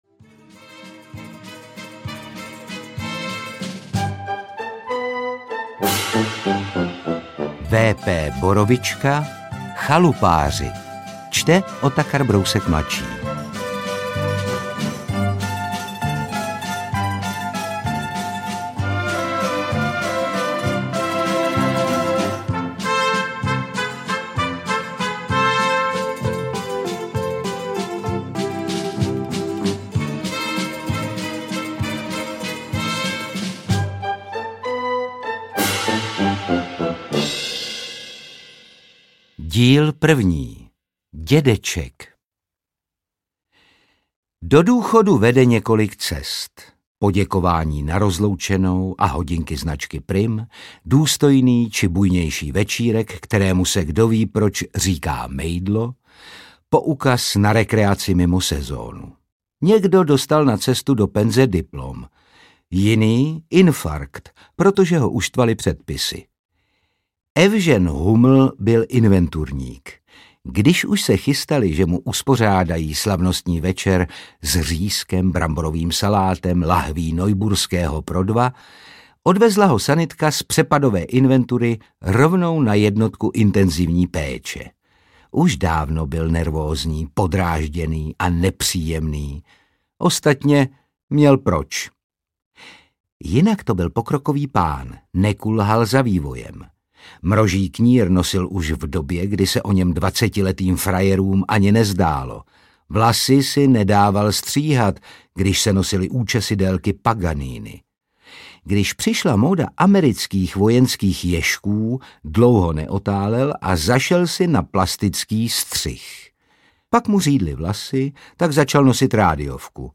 Čte Otakar Brousek mladší.
Ukázka z knihy
Příhody nerudného revizora na penzi a jeho svérázného nájemníka z chalupy s orchestrionem nyní ožívají v podobě audioknihy. Nahrávku doplňuje původní seriálová melodie a jako bonus písnička Waldemara Matušky „Když máš v chalupě orchestrion“.
• InterpretOtakar Brousek ml.